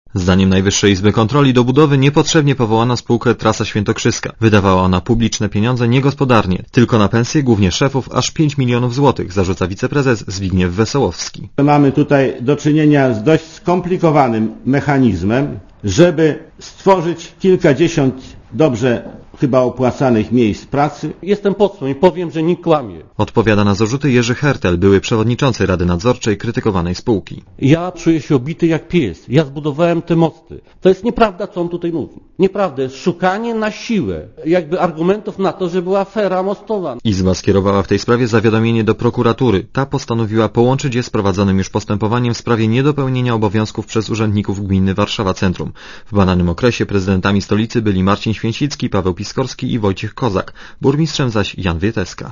Relacja reportera Radia ZET Jak poinformował we wtorek na konferencji prasowej w Warszawie wiceprezes NIK Zbigniew Wesołowski, do realizacji przedsięwzięcia powołano, jako inwestora, spółkę Trasa Świętokrzyska.